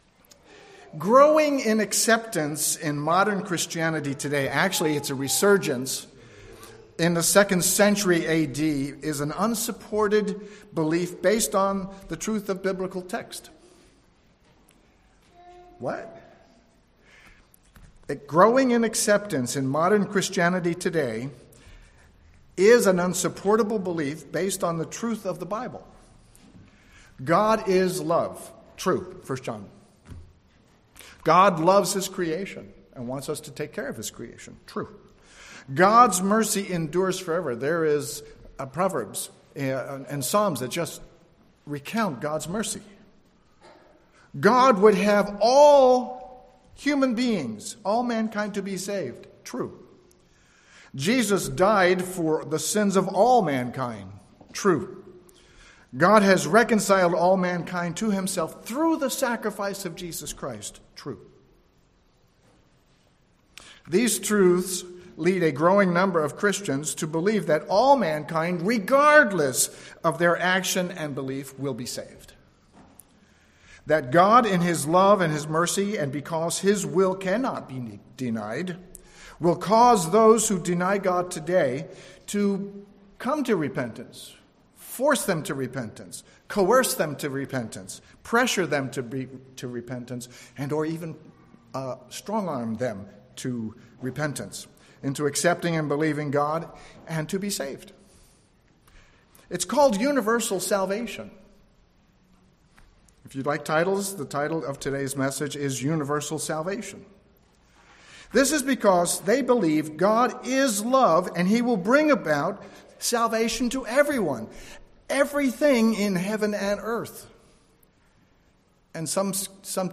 Listen to this sermon to learn why "Universal Salvation" is not based on biblical truth, and that salvation is a conditional gift.
Given in San Jose, CA